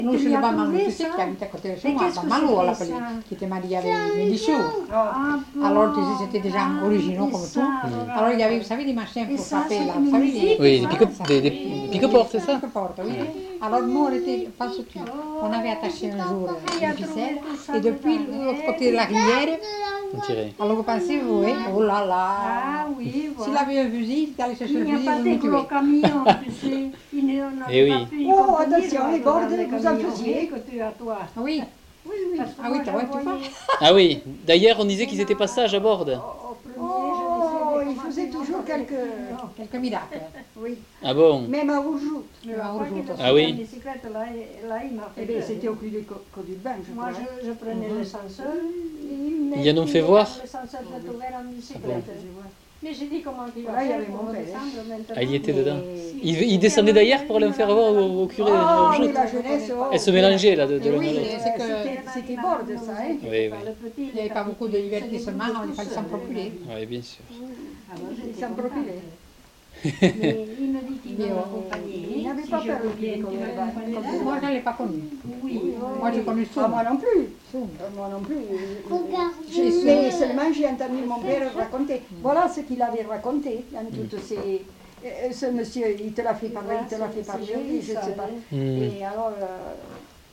Aire culturelle : Couserans
Lieu : Montjoie-en-Couserans
Genre : témoignage thématique